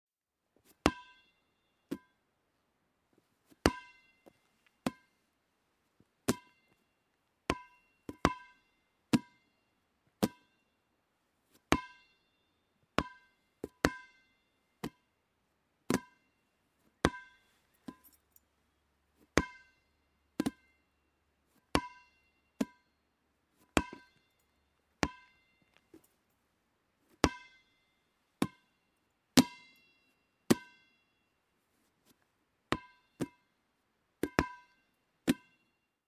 На этой странице собраны звуки мяча в разных ситуациях: удары, отскоки, броски.
Звук детской игры с резиновым мячом